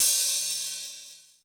SIMM HAT OP2.wav